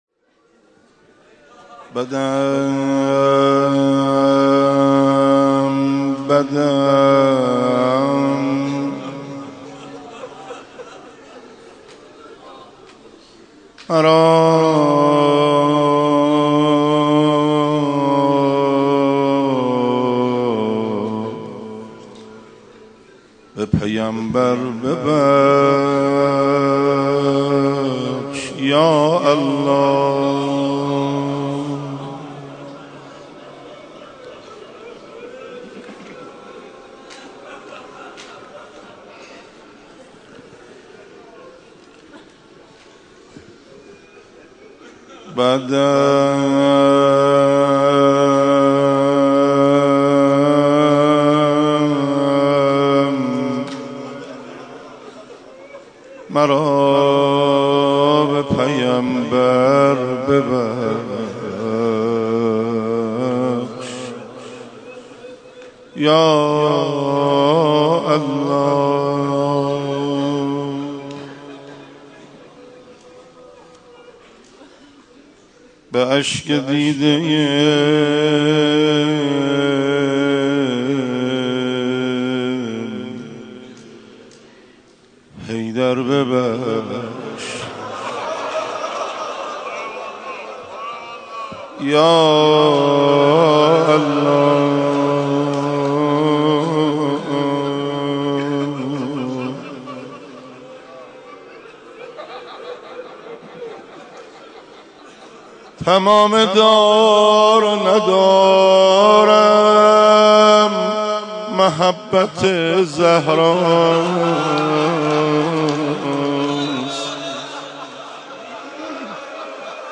مناجات با خدا